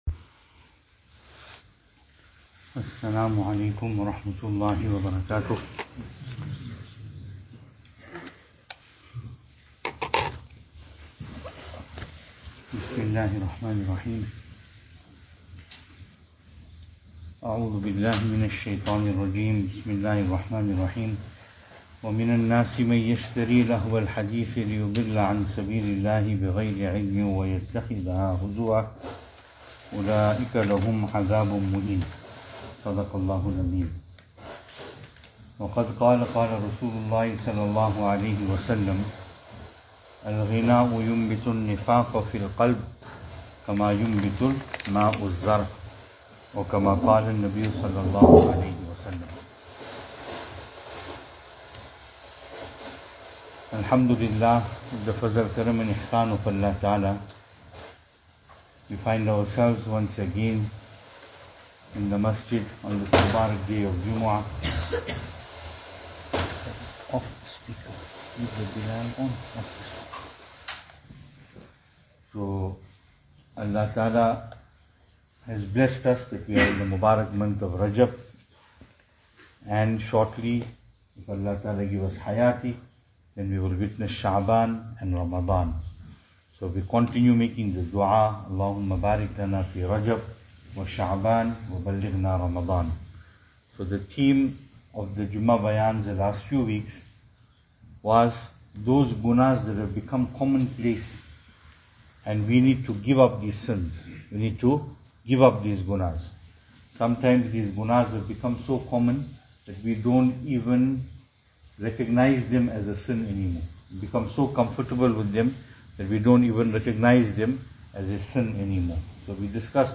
2022-02-18 Prohibition Of Music Venue: Albert Falls , Madressa Isha'atul Haq Service Type: Jumu'ah